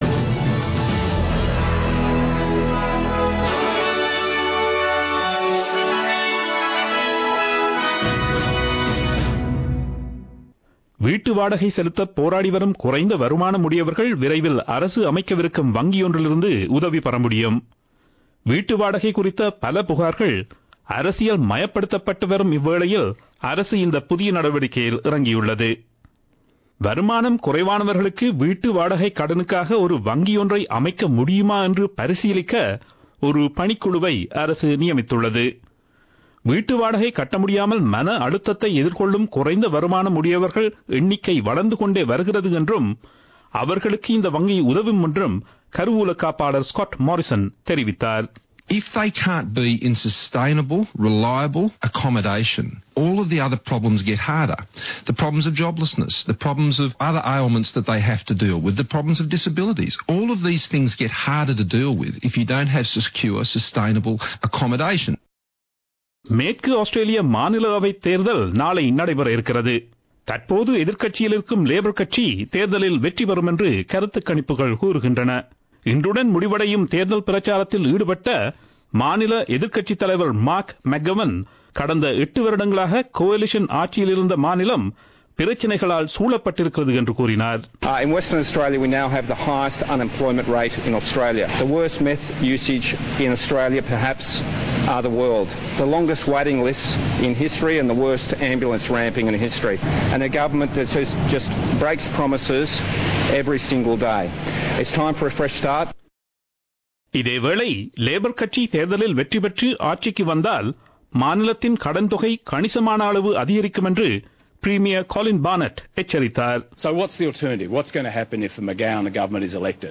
Australian news bulletin aired on Friday 10 Mar 2017 at 8pm.